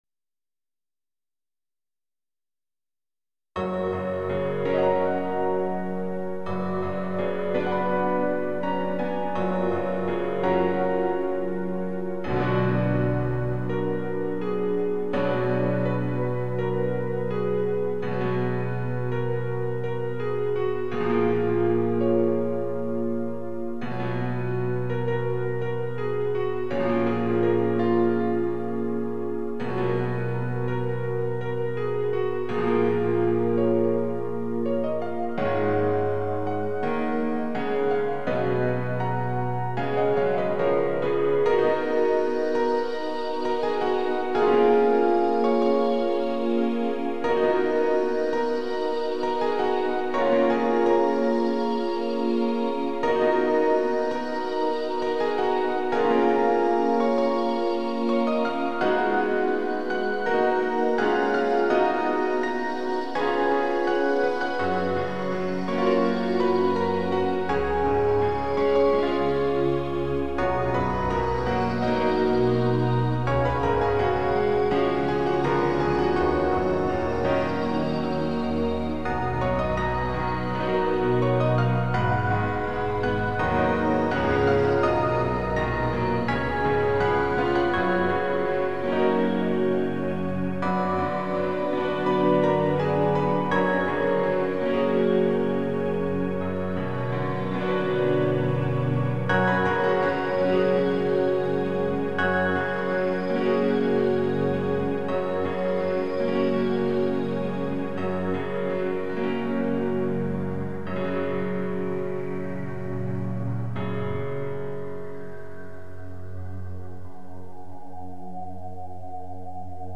哀しい雰囲気を前面に出してます。
my_place_piano_gm.mp3